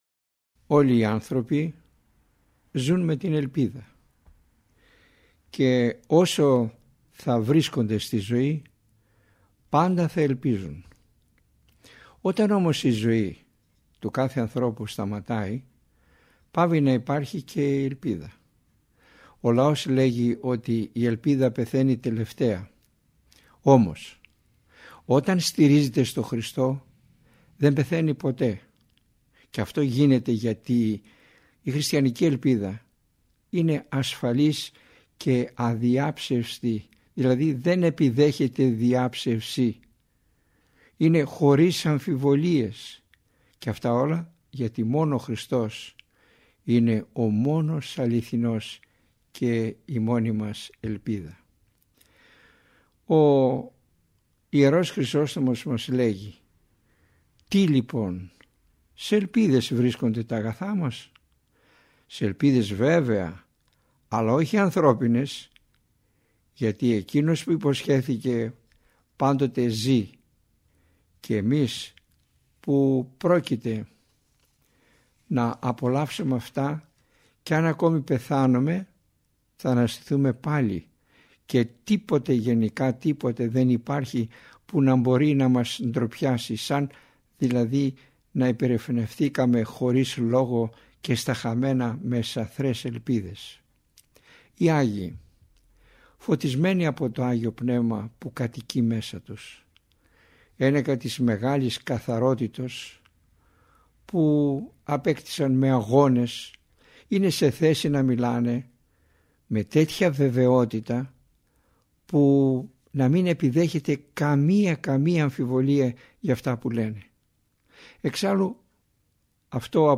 Ελευθερία επιλογής Το μεγάλο πλεονέκτημα των ομιλιών Κάθε ομιλία είναι ένα ζωντανό κήρυγμα, όπου το παν εξαρτάται από τη θέληση του ακροατή˙ ο τόπος, ο χρόνος και ο τρόπος ακρόασης, το θέμα της ομιλίας εναπόκεινται στην προσωπική του επιλογή.